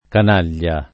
canaglia [ kan # l’l’a ]